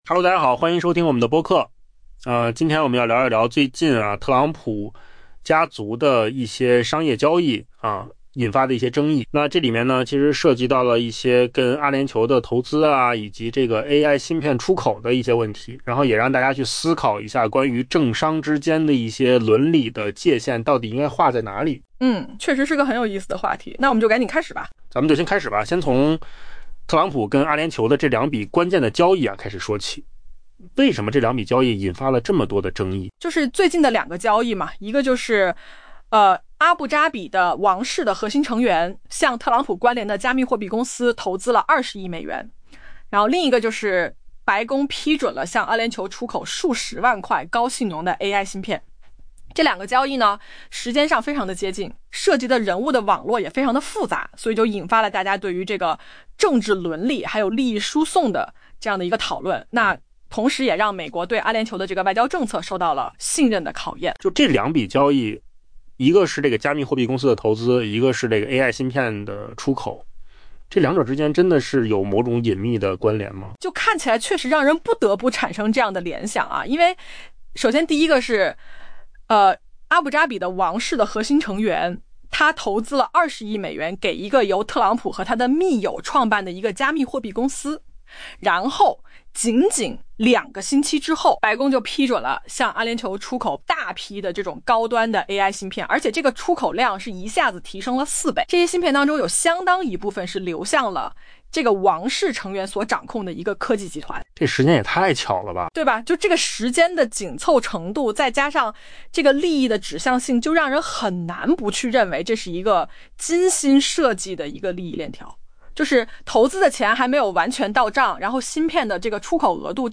AI 播客：换个方式听新闻 下载 mp3 音频由扣子空间生成 美国总统特朗普与阿联酋的关系又出现最新演变，两笔相隔仅两周的交易引发广泛争议。